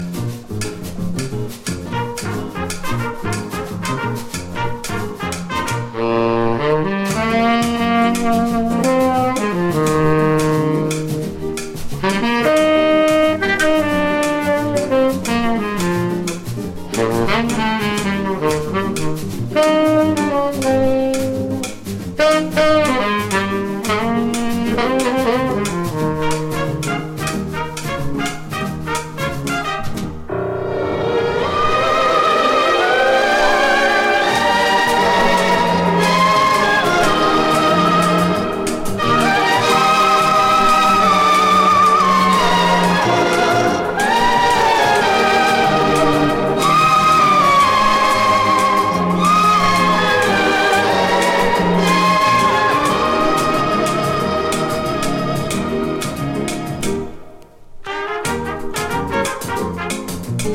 ドイツ・イージーリスニング・コンポーザー。